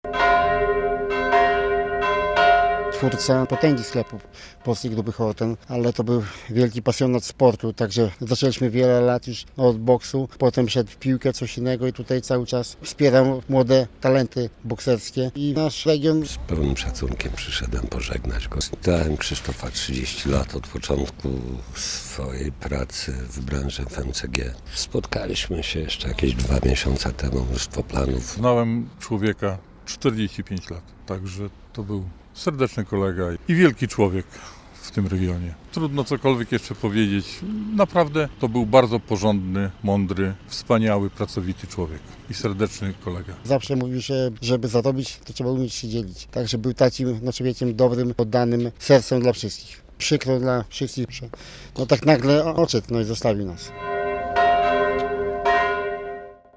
Pogrzeb
relacja